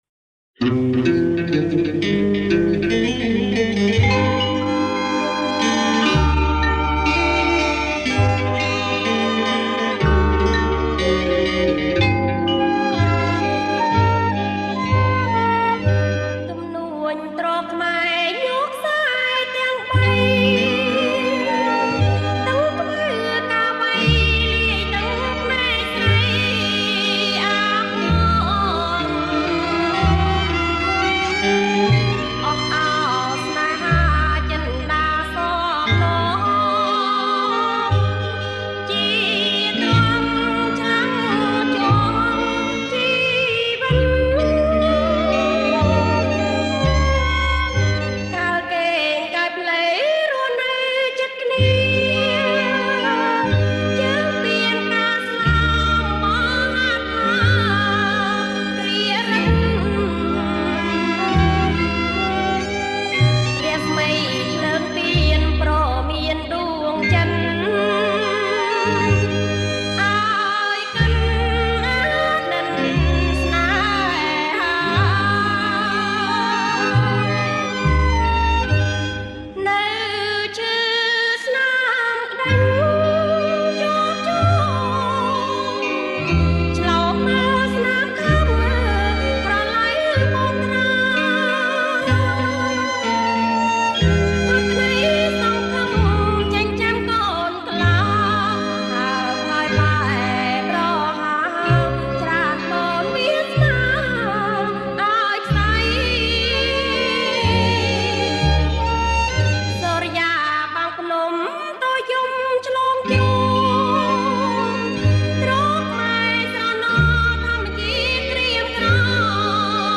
• ប្រគំជាចង្វាក់ Bolerro Lent
ប្រគំជាចង្វាក់ Slow